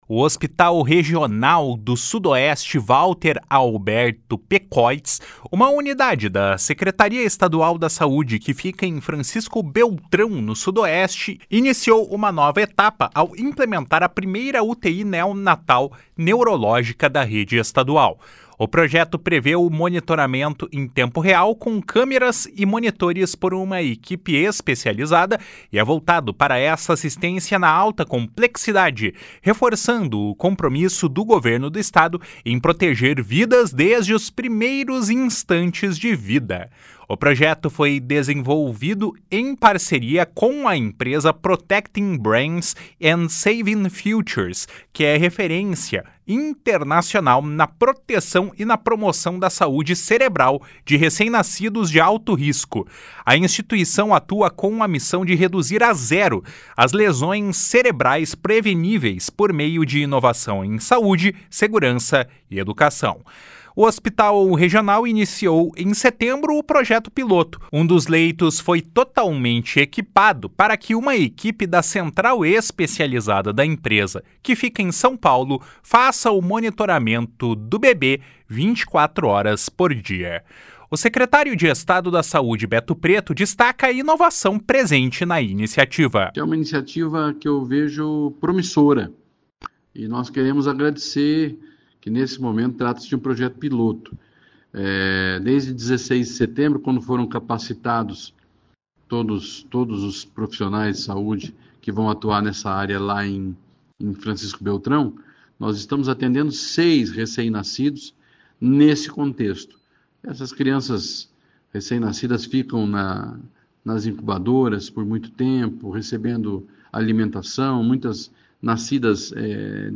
O secretário estadual da Saúde, Beto Preto, destaca a inovação presente na iniciativa.